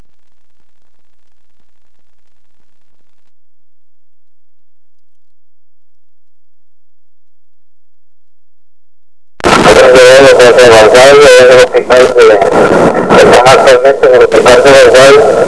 Escuche entrevista. 2 accidentes ha tenido el Batall�n Cuscatl�n desde que lleg� a la ciudad de Hilla.